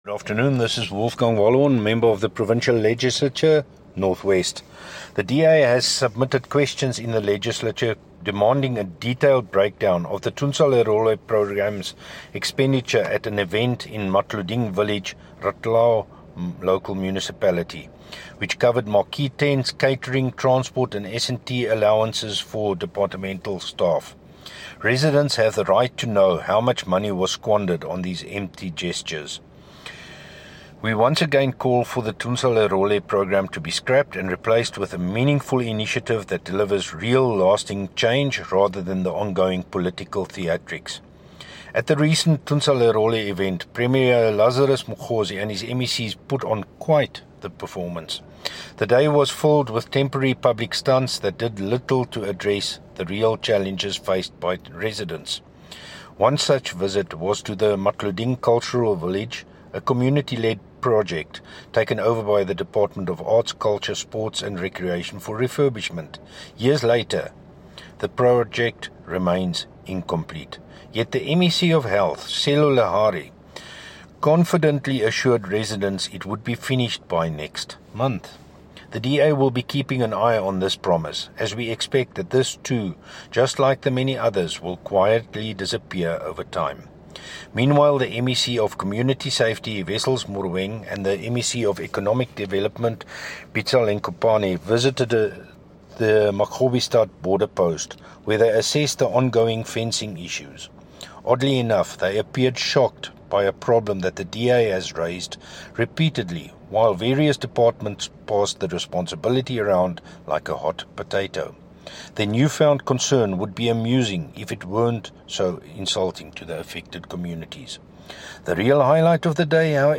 Issued by Wolfgang Wallhorn – DA Spokesperson on the Office of the Premier in the North West Provincial Legislature
Note to Broadcasters: Please find linked soundbites in